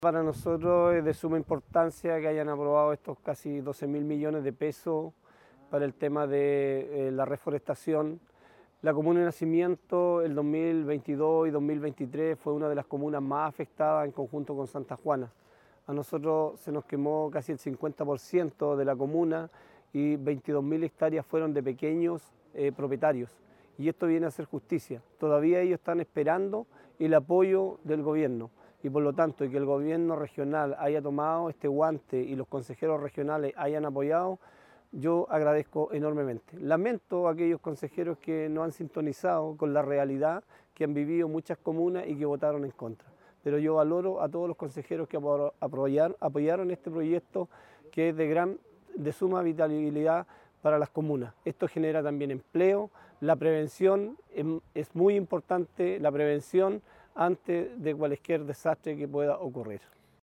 Asimismo, el alcalde de Nacimiento, Carlos Toloza, recordó que su comuna fue arrasada en un 50% para los incendios del 2023 y precisó que 22 mil de las hectáreas afectadas “fueron de pequeños propietarios y esto viene a hacer justicia”, señaló.